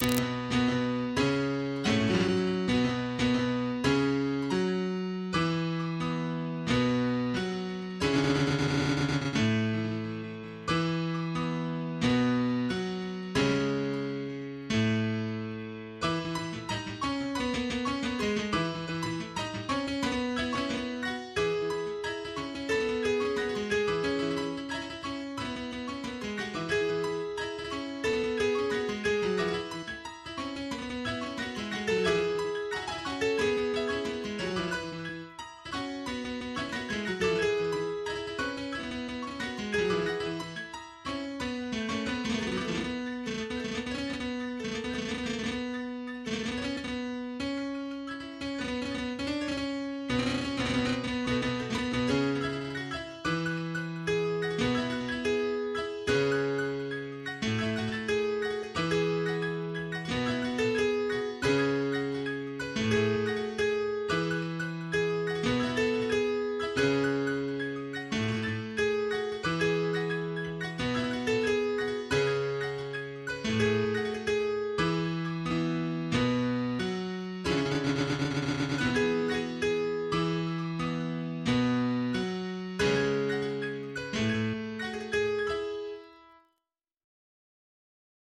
MIDI 17.23 KB MP3